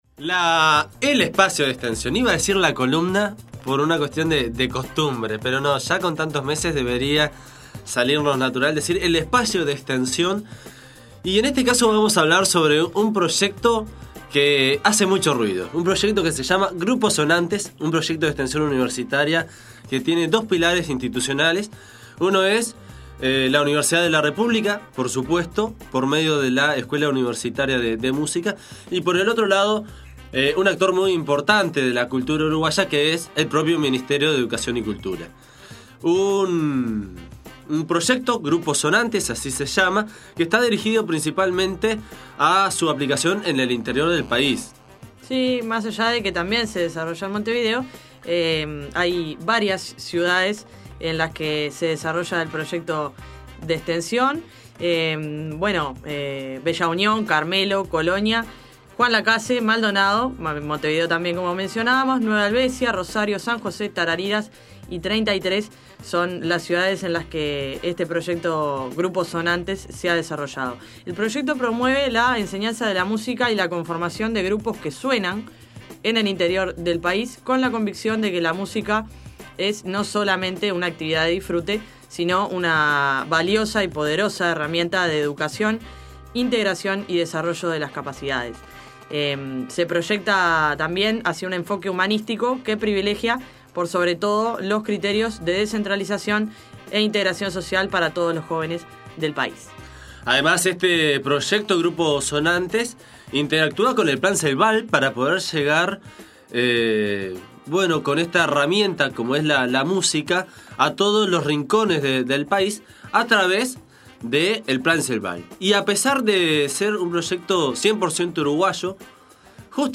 Audio: Proyecto de Extension Grupos Sonantes. Entrevista